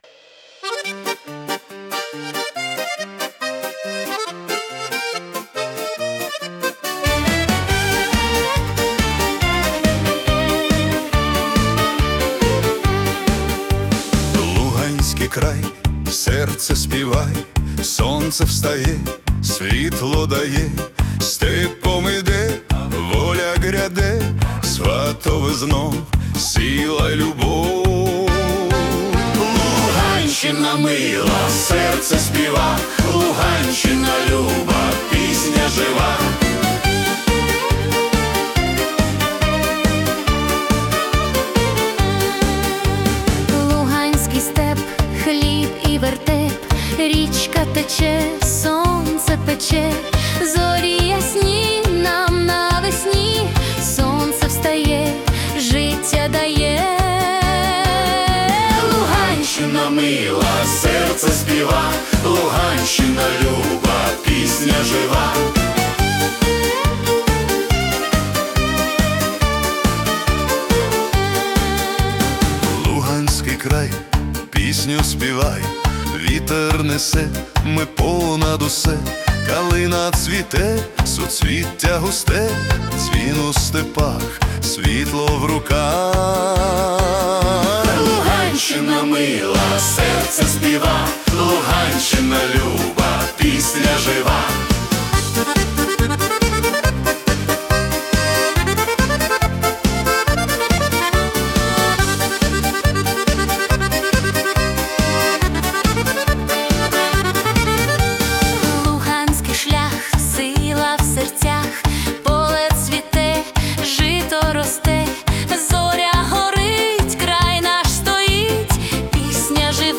Ukrainian Polka / Folk-Pop